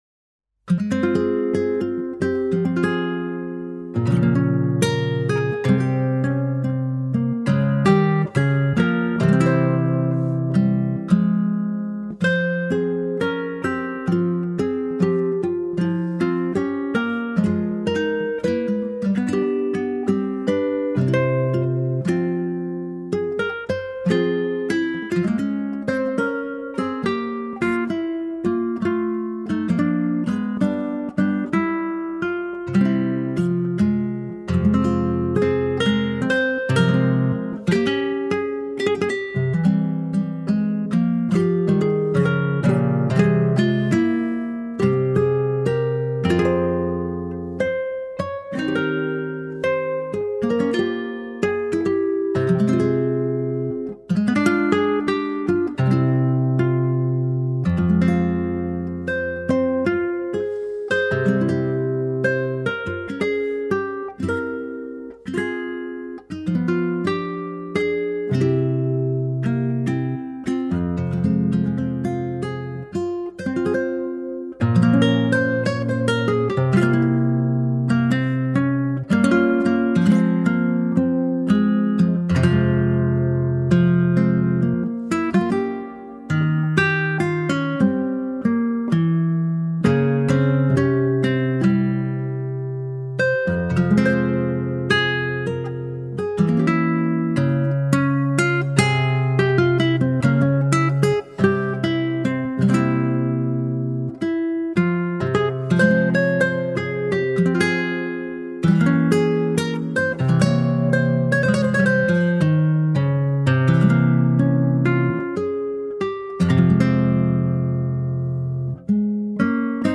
Largo [0-10] - - guitare - harpe - aerien - folk - melodieux
guitare - harpe - aerien - folk - melodieux